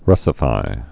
(rŭsə-fī)